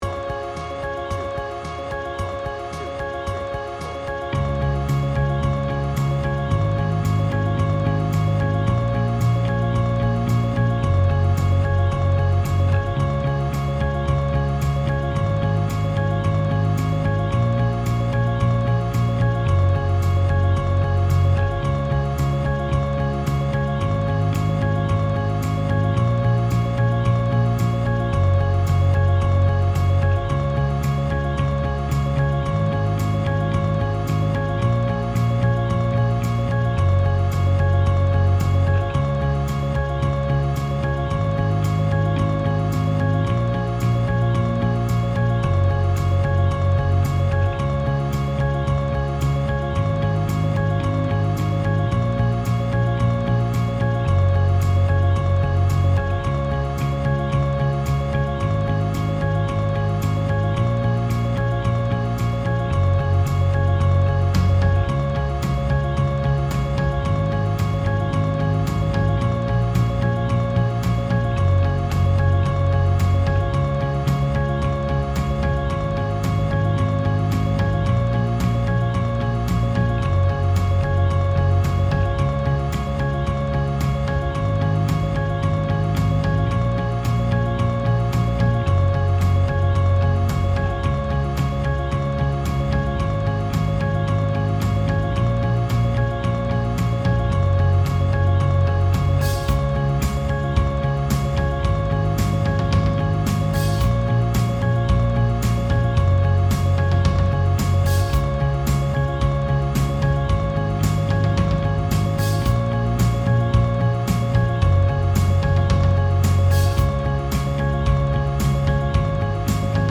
BPM : 111
Tuning : Eb
Without vocals